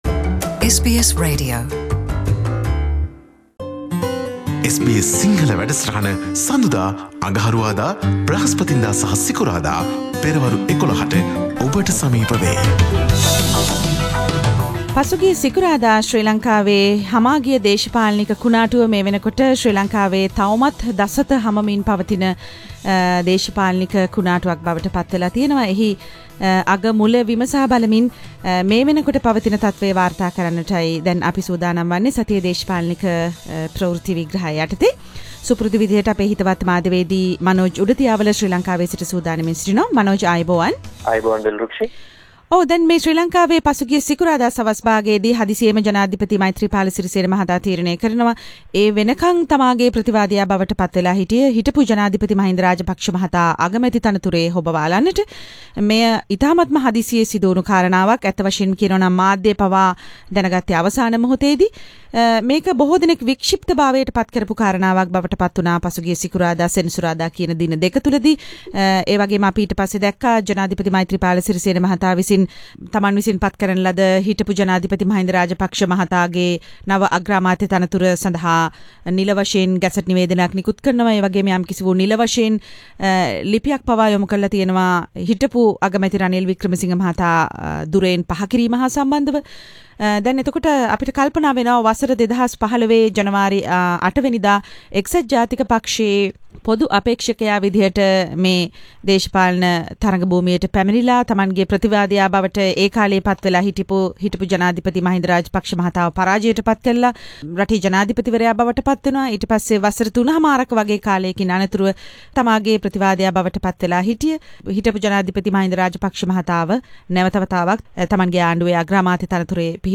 පුවත් සමාලෝචනය